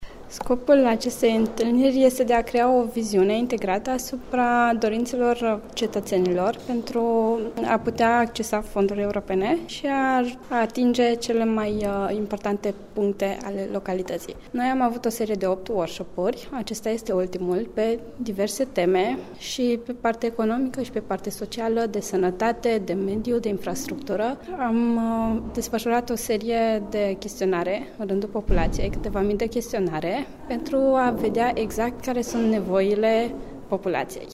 consultant fonduri europene